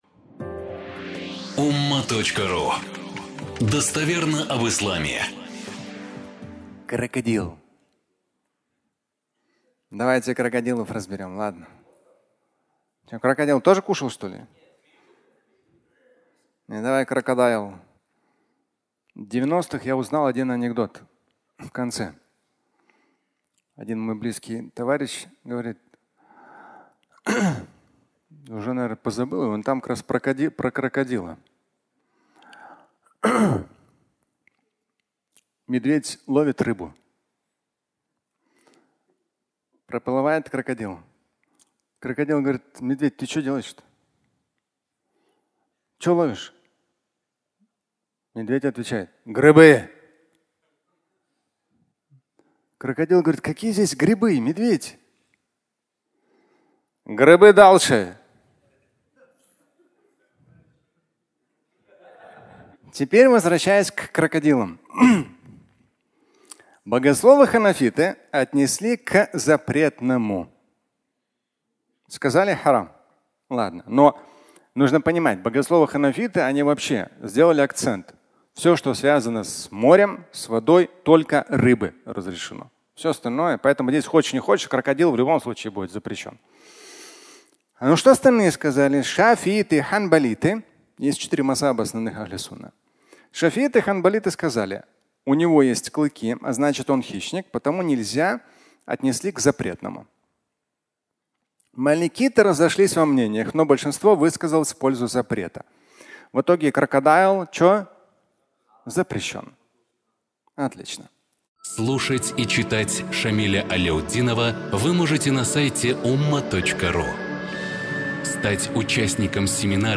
Крокодил (аудиолекция)